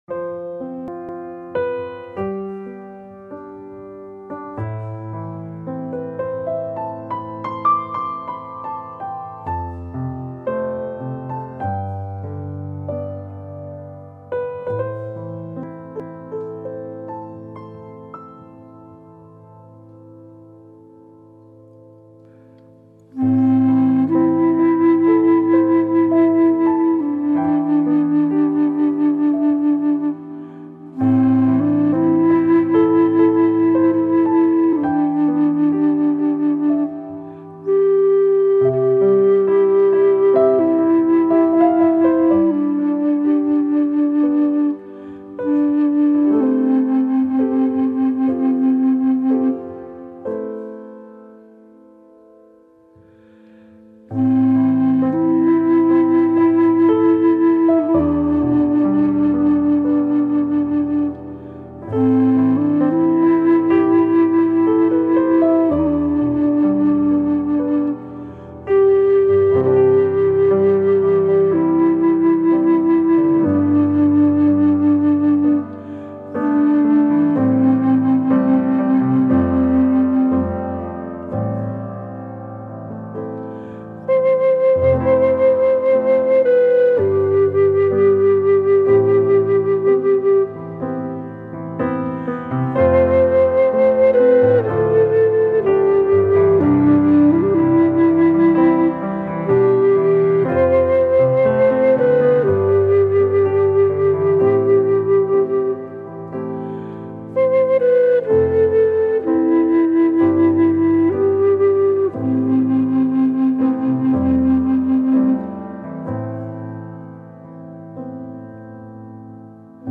قطعه ای زیبا از همنوازی پیانو و فلوت : "عشق و مهربانی"